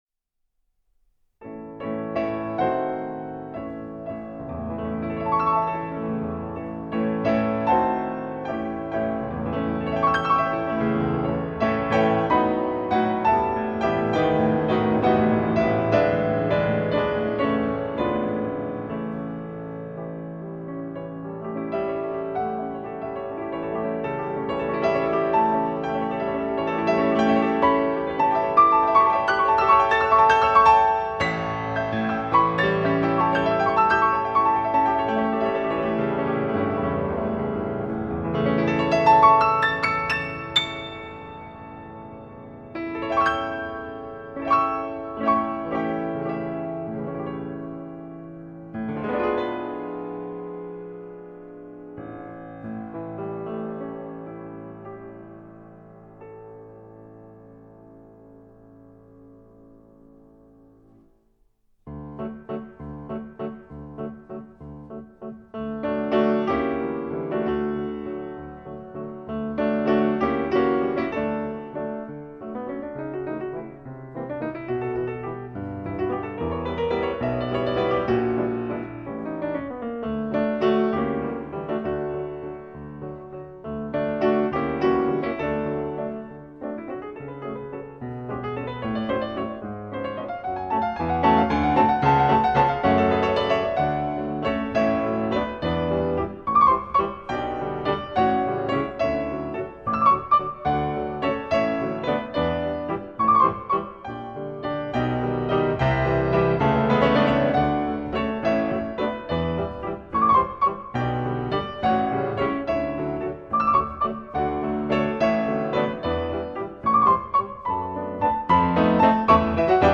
Жанр: Classical